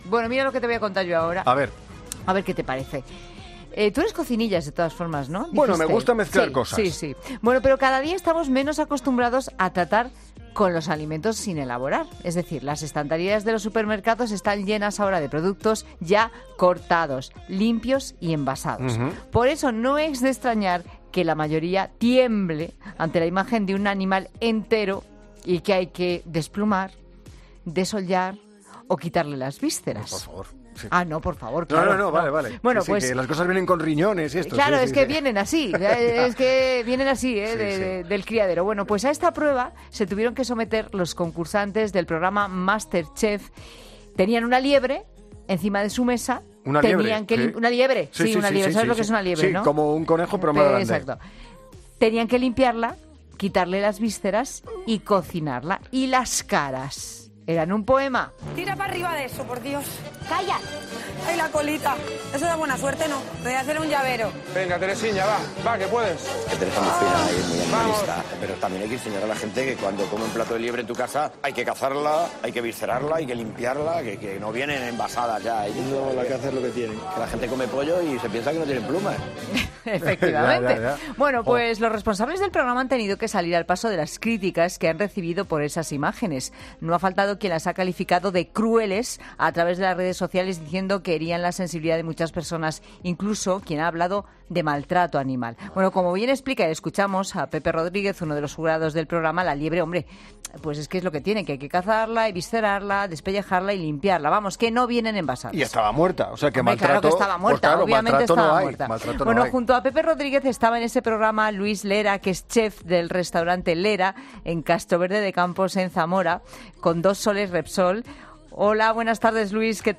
es un magazine de tarde que se emite en COPE